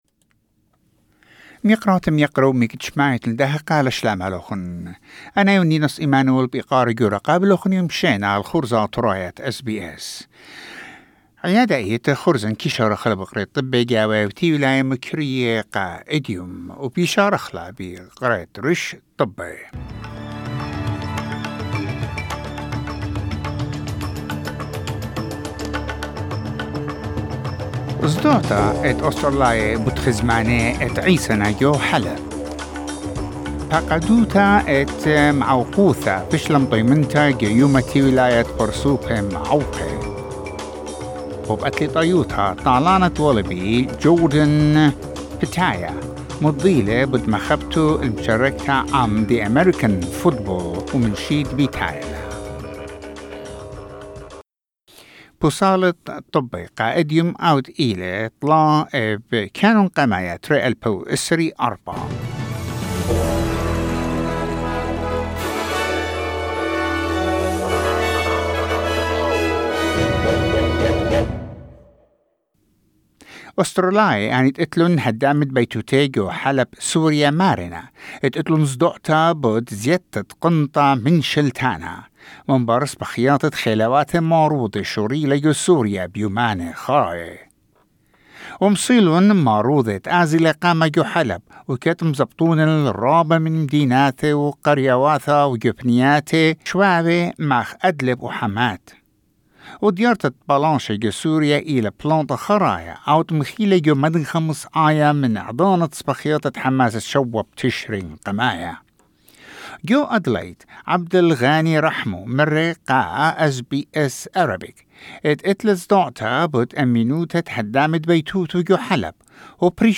SBS Assyrian news bulletin: 3 December 2024